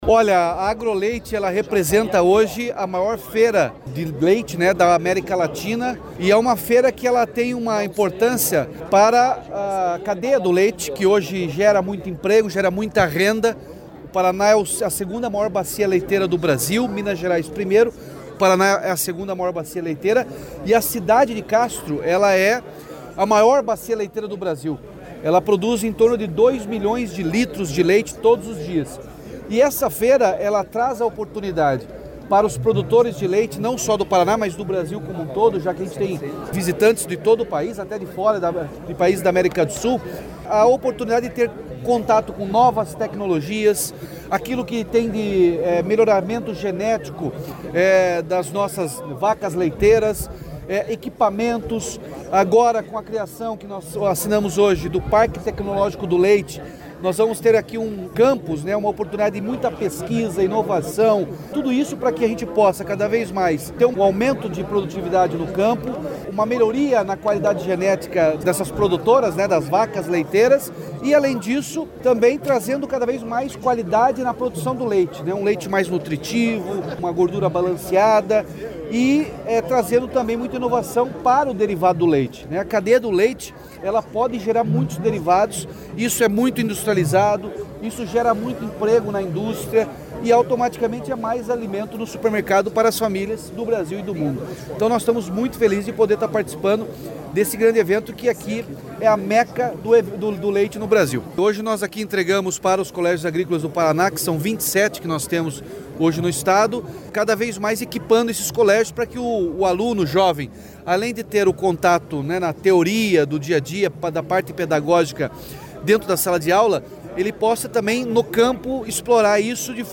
Sonora do governador Ratinho Junior sobre a criação do Parque Tecnológico Agroleite e os investimentos para colégios agrícolas do Estado